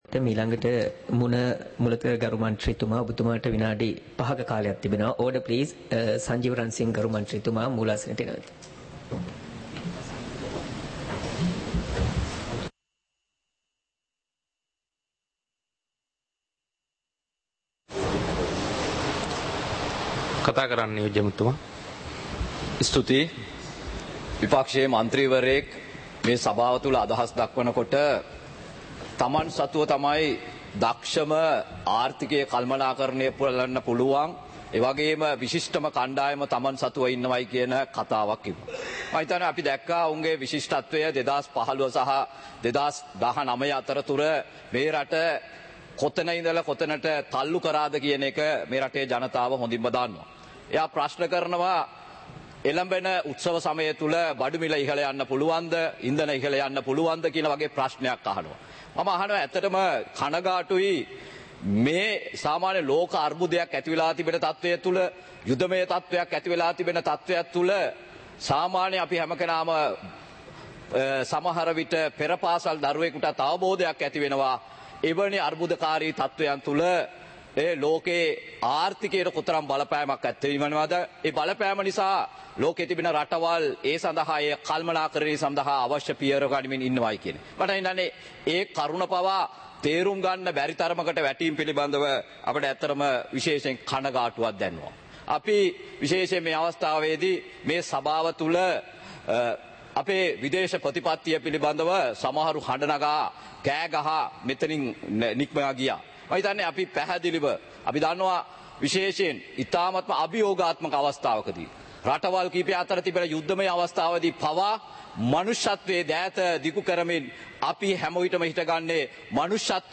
சபை நடவடிக்கைமுறை (2026-03-19)